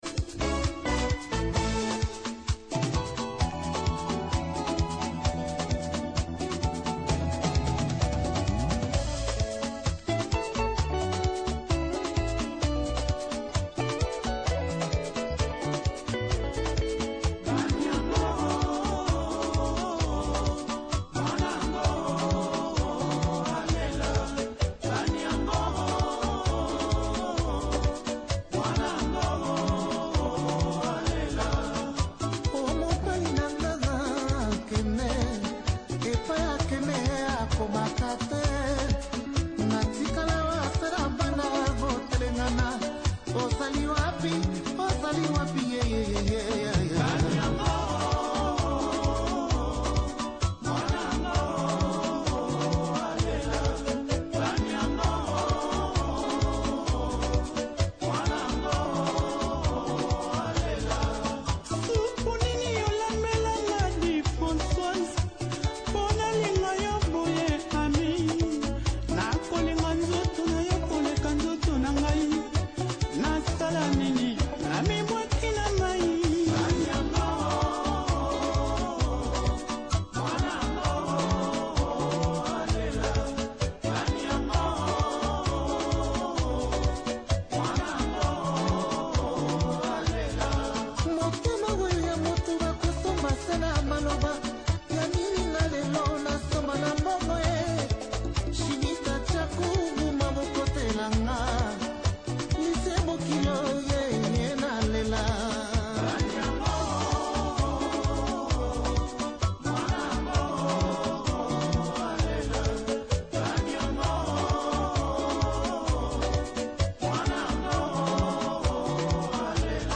#90smusic